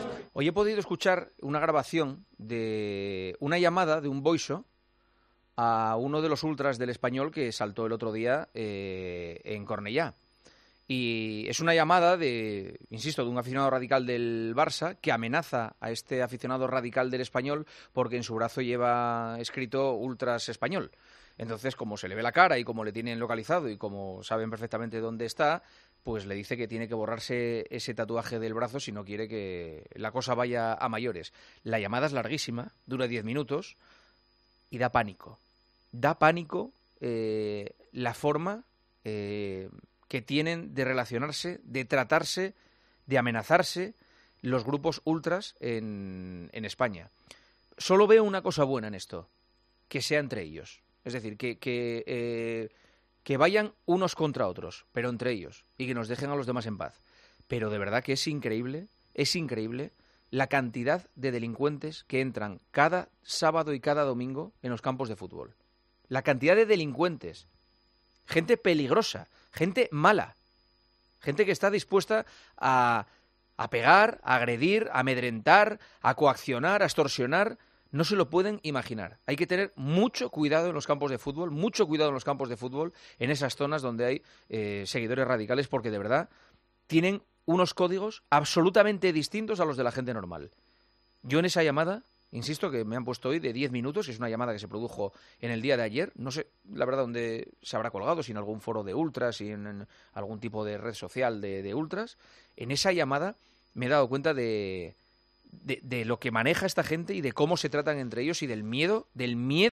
El presentador de El Partidazo de COPE reflexionó sobre la conversación a la que tuvo acceso entre un seguidor radical del Barcelona y un ultra del Espanyol después del derbi.